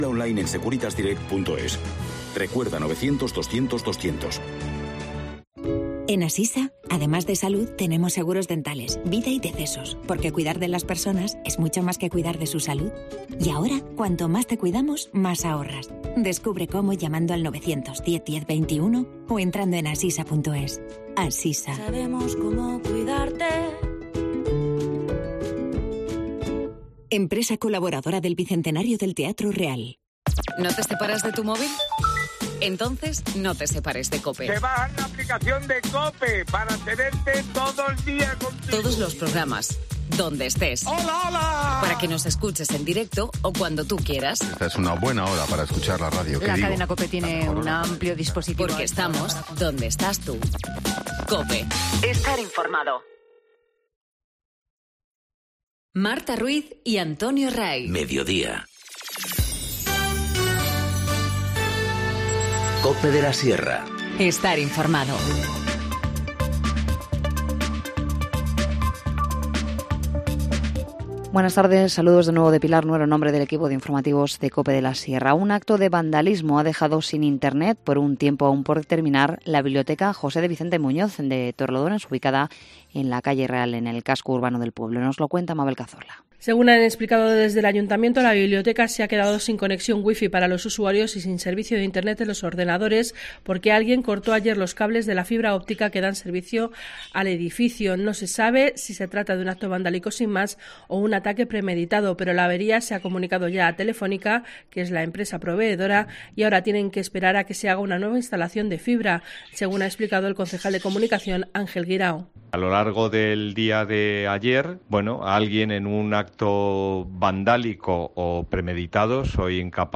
Informativo Mediodía 9 enero- 14:50h